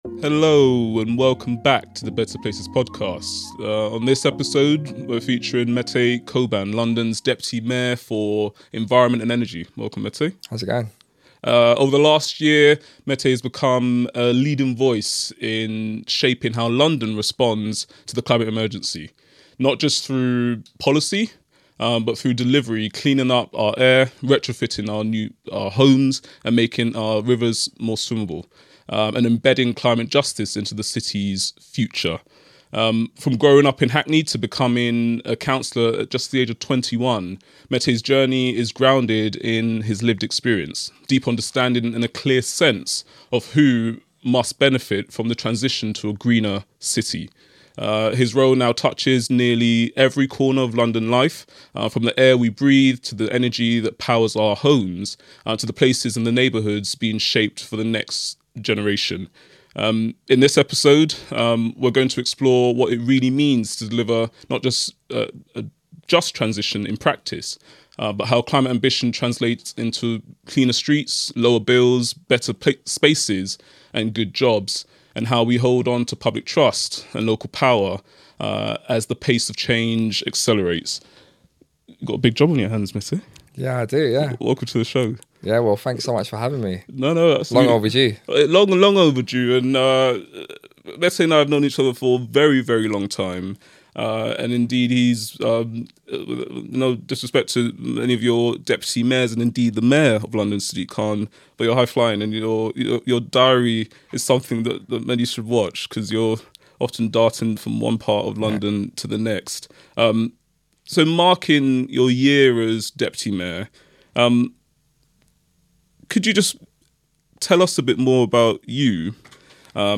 The conversation explores what it means to deliver climate action at the scale of a global city while keeping communities, fairness, and placemaking at the heart of that mission. We discuss London’s retrofit revolution, the drive to make rivers swimmable within a decade, and how planning and investment can support greener, healthier neighbourhoods.